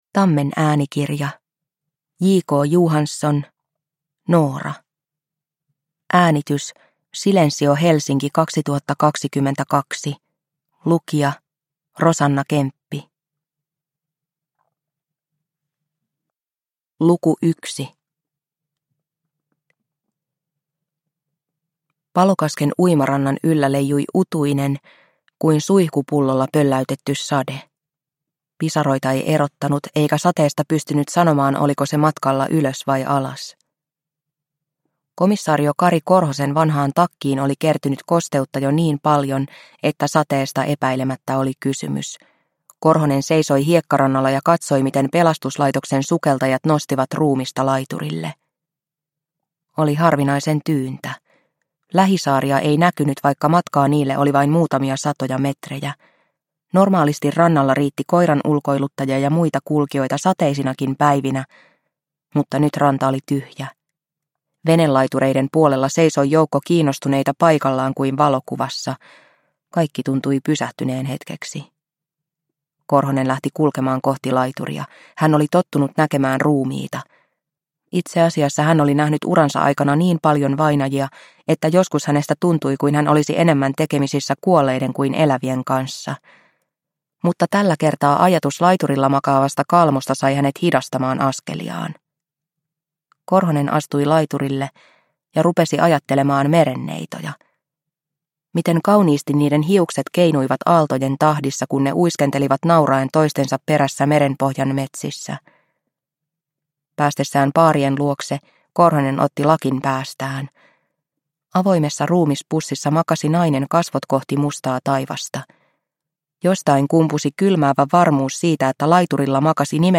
Noora (ljudbok) av J. K. Johansson